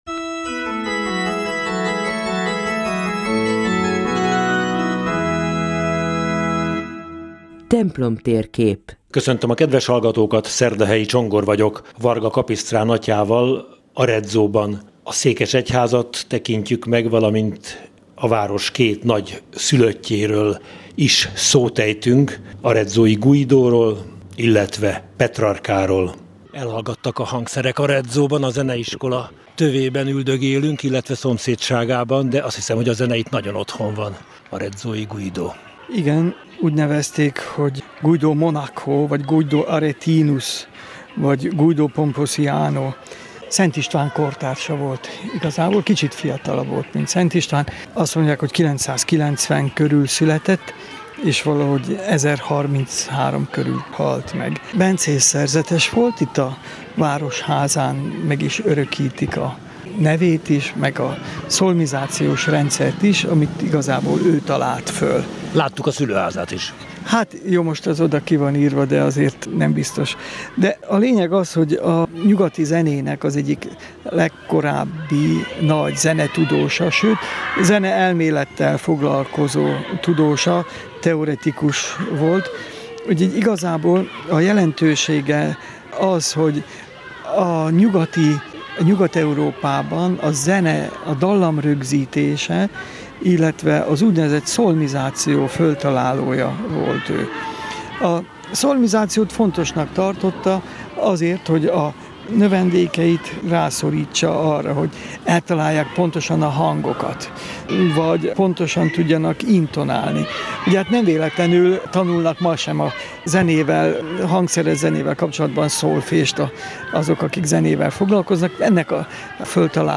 A Katolikus Rádióban július 22-én elhangzott műsor itt meghallgatható.